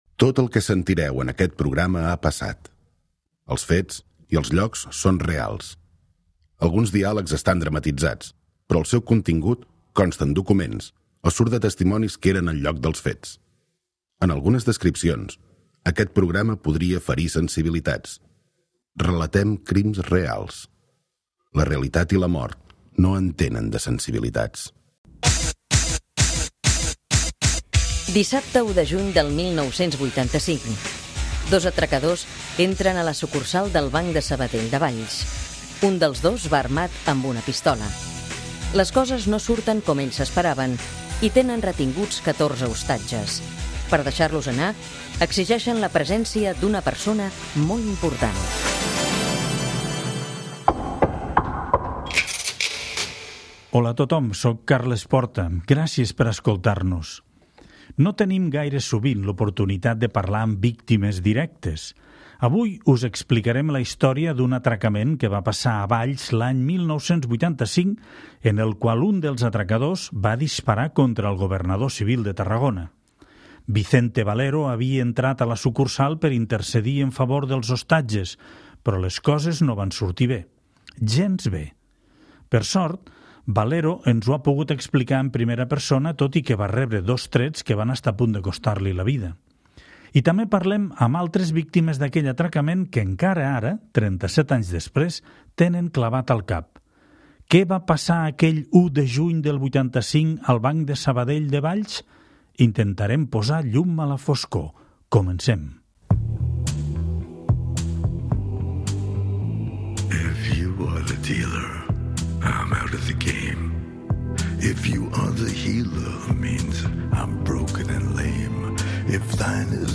Per sort, Valero ens ho ha pogut explicar en primera persona tot i que va rebre dos trets que van estar a punt de costar-li la vida. I també parlem amb altres víctimes d'aquell atracament que encara ara, 37 anys després, tenen clavat al cap.